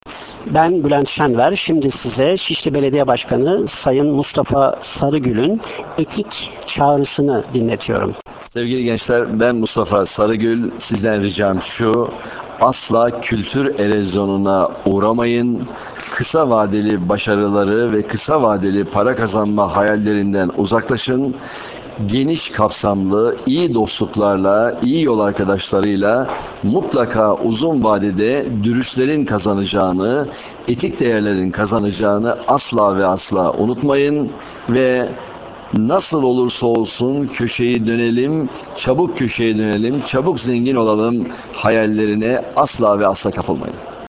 Kendi Sesiyle
MUSTAFA SARIGÜL - Gençliğe Seslenişini kendi sesinden dinlemek isterseniz, bilgisayarınızın sesini açıp,